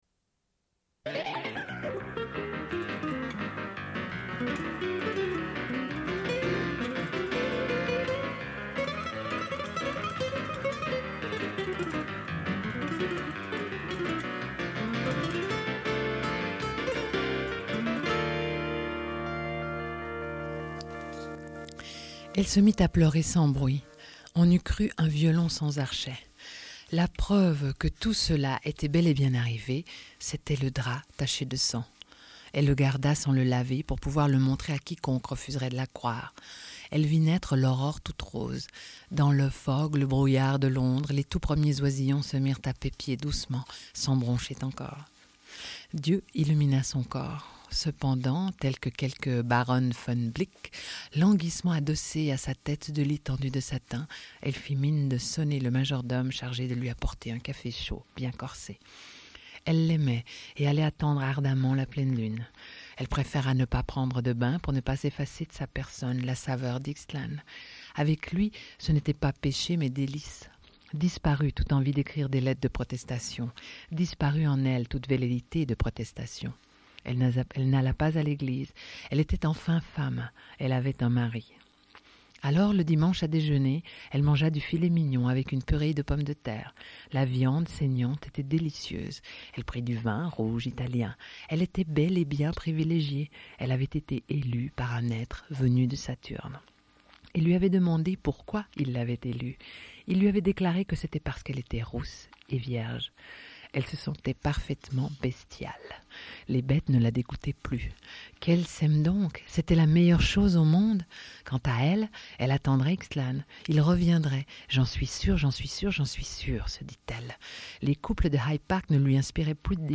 Suite de l'émission : au sujet de Clarice Lispector, écrivaine brésilienne. Lecture d'extraits de ses nouvelles Amour, Miss Algrane et Bruit de Pas.